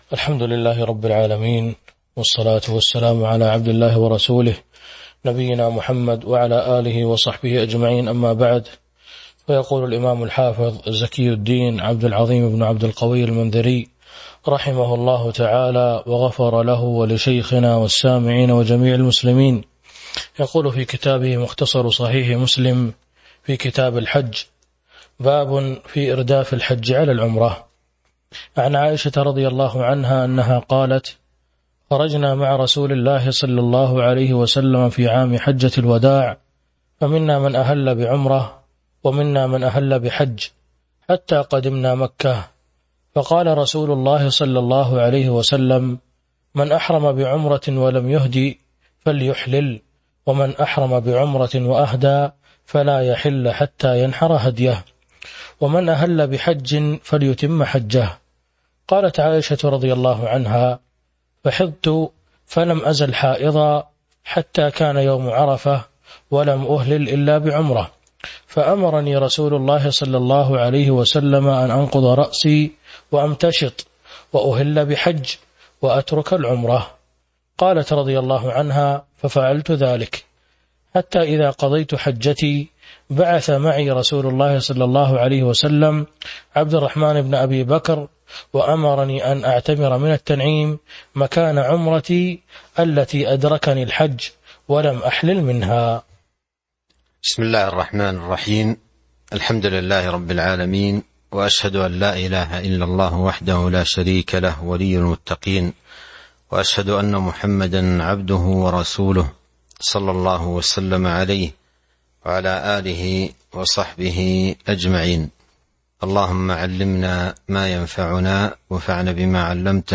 تاريخ النشر ٢٨ ذو القعدة ١٤٤٢ هـ المكان: المسجد النبوي الشيخ